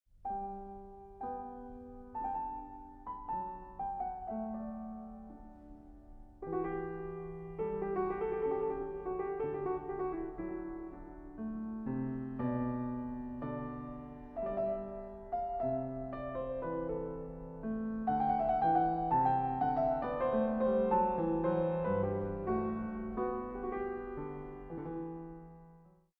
Piano
Trackdown Studios